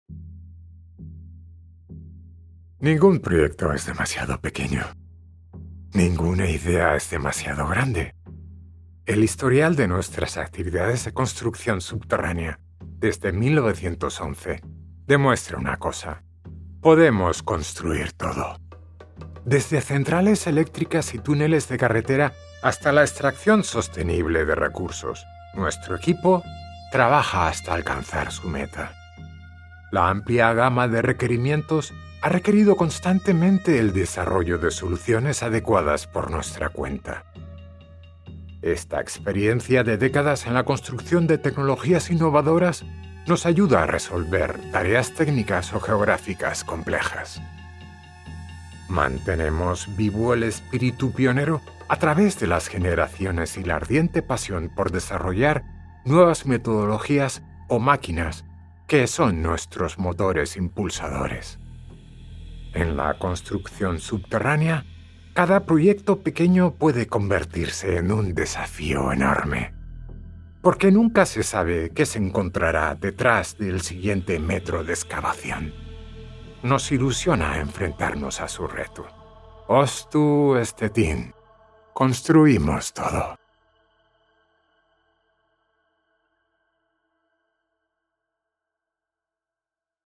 Spanish
Smooth
Warm
Mesmerizing